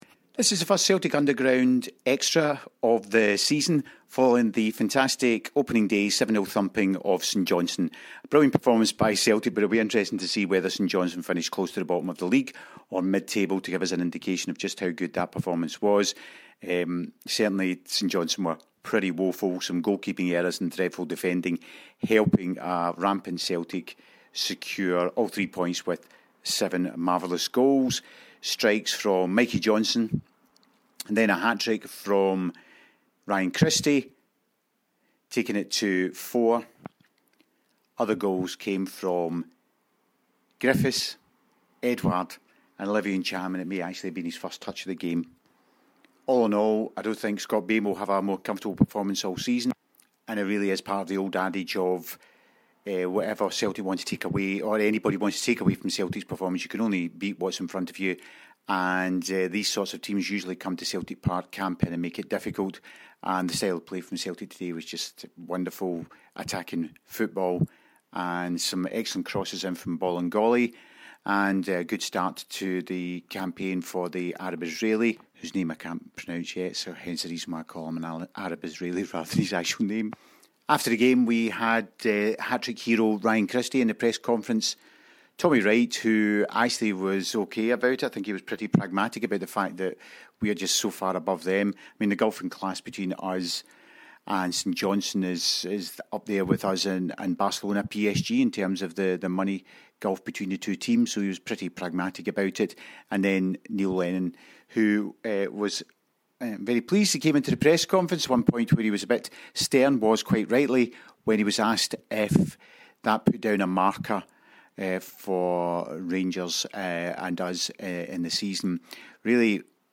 Season 2019/20 couldn’t have got off to a better start and we hear from Ryan Christie and Neil Lennon as they reflect on a perfect afternoon’s work.